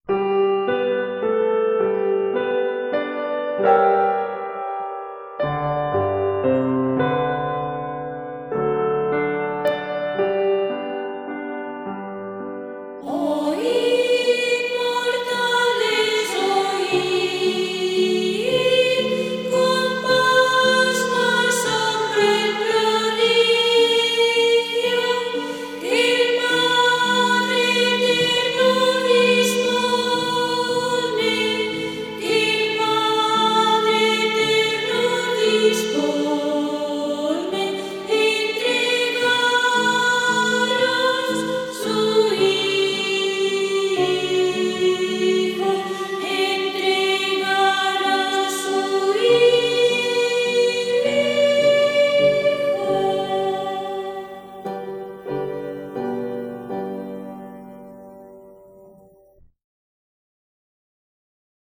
Sarsuela pastoril
Enregistrat el 6 de juliol de 1977 al Centre Catòlic d'Olot.